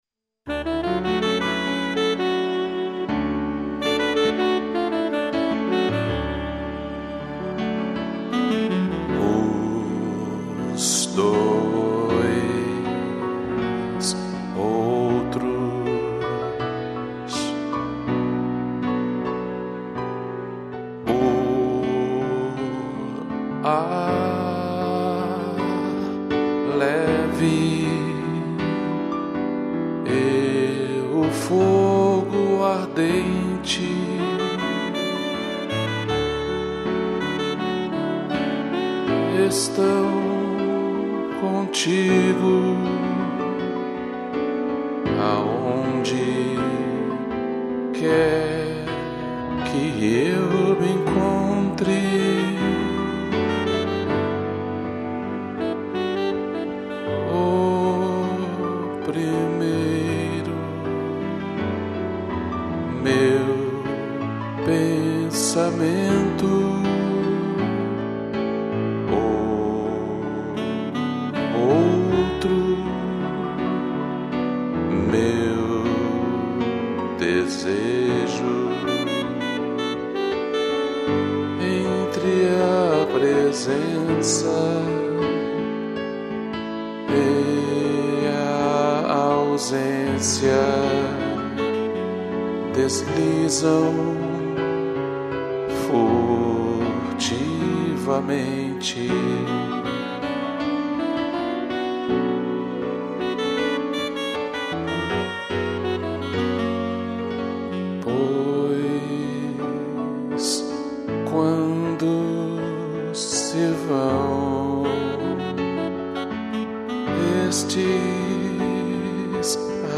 2 pianos, tutti e sax